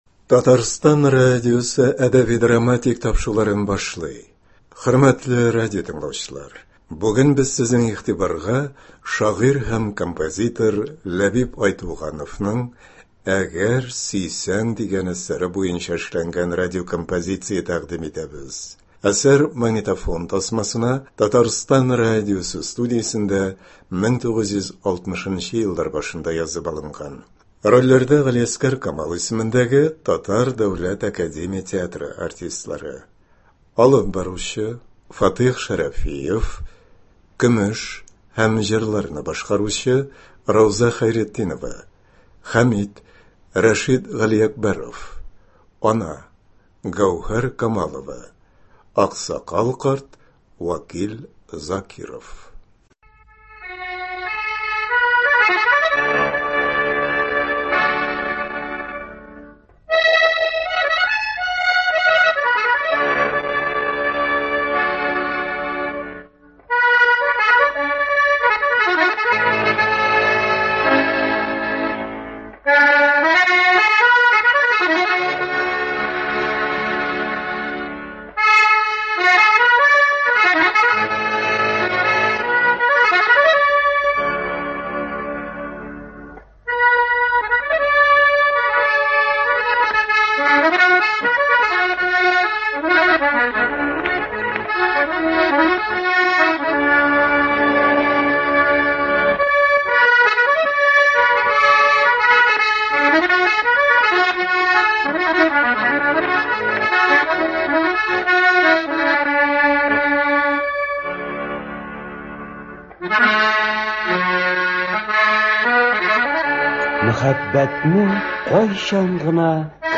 Радиокомпозиция.
Әсәр магнитофон тасмасына Татарстан радиосы студиясендә 1960 еллар башында язып алынган. Рольләрдә Г.Камал исемендәге Татар дәүләт академия театры артистлары.